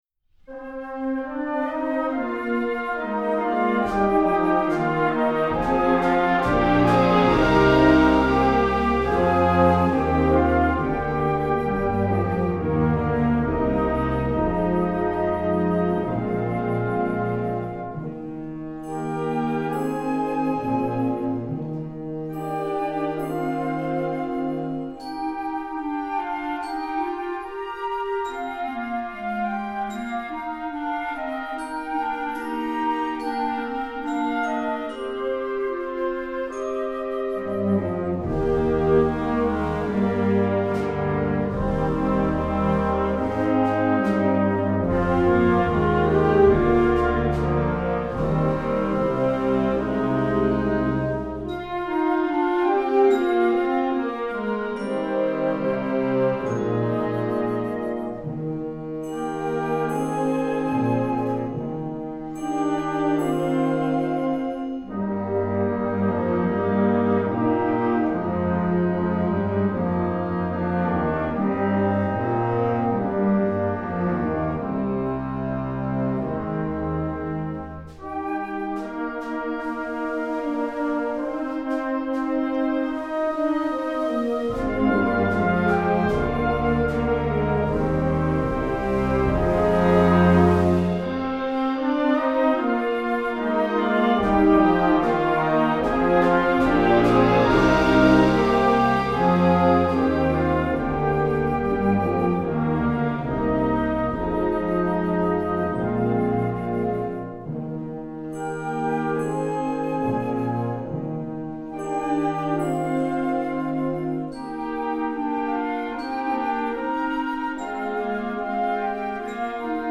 Composer: Traditional
Voicing: Concert Band